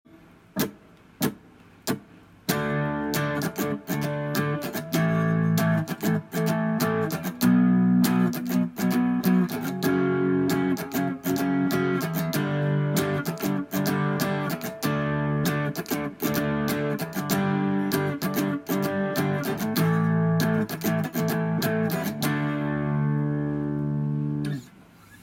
4弦ルートのパワーコードを混ぜることでフレットの移動を減らすの試してた。